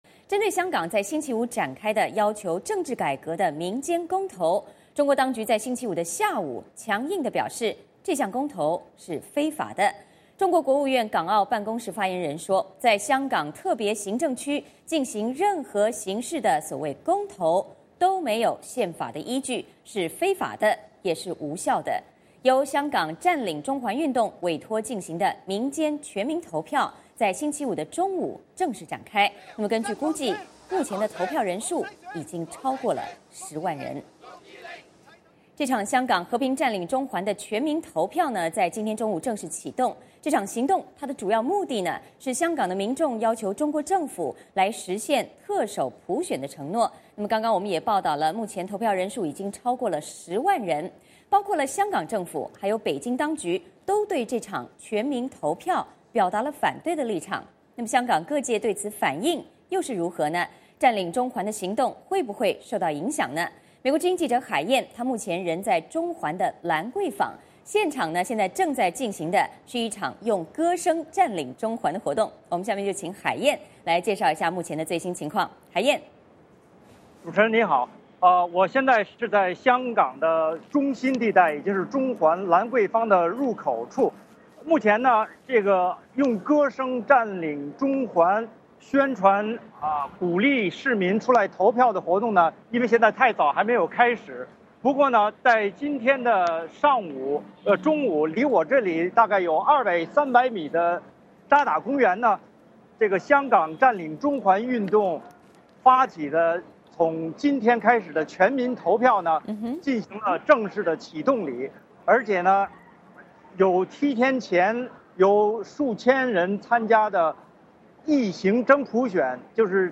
现场正在进行的是用“歌声占领中环”活动